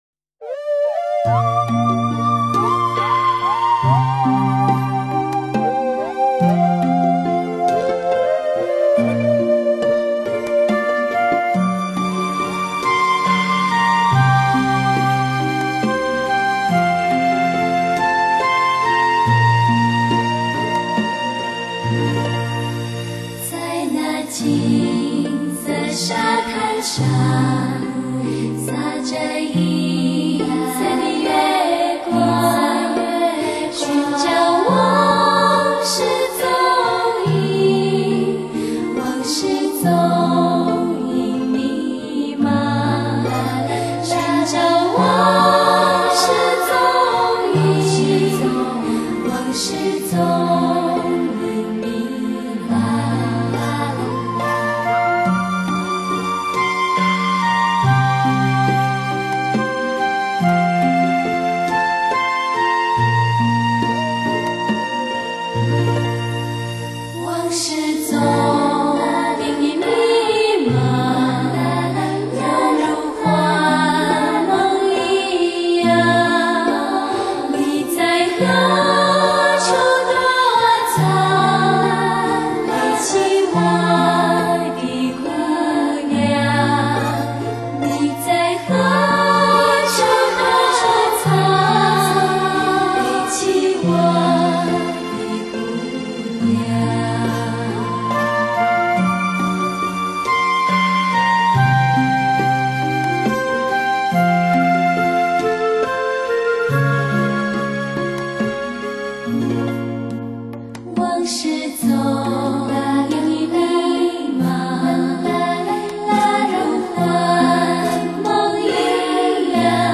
演唱者的水平也不低。
低品质